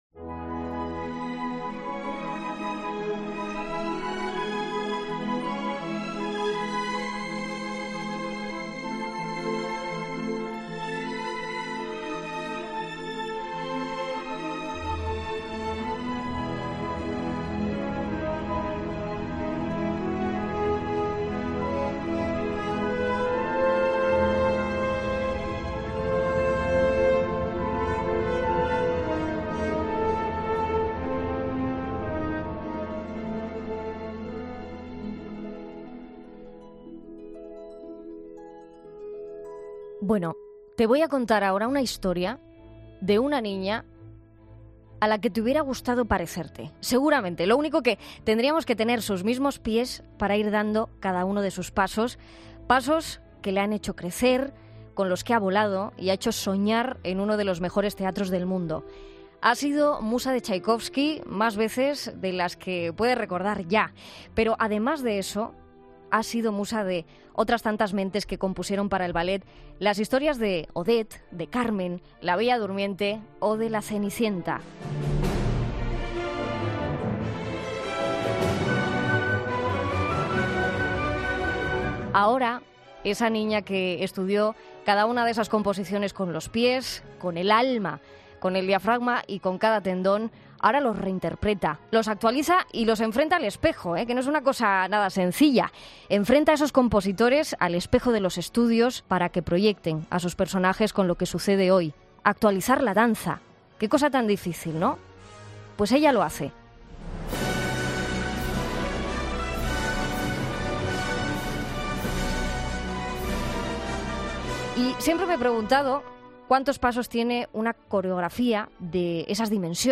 Escucha la entrevista completa a Tamara Rojo en el programa 'Fin de Semana'